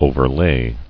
[o·ver·lay]